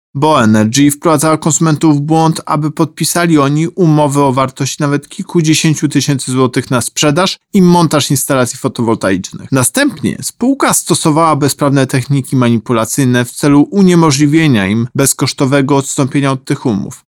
Karę nałożył na spółkę Urząd Ochrony Konkurencji i Konsumentów za wprowadzanie w błąd klientów oraz utrudnianie im szybkiego odstąpienia od umowy. Mówi o tym prezes UOKiK, Tomasz Chróstny.